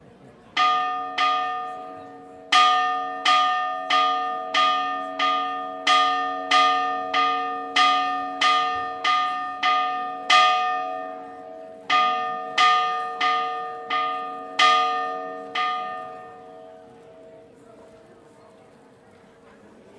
描述：在Baiona（庞特维德拉）的Misericordia（Mercy）教堂记录（兄弟）会议的响声。2015年8月15日，19：15：08.MS侧微观水平：角度幅度为90度。
Tag: 慈悲 教堂 巴约纳 铃换会议 现场录音 VAL-Minhor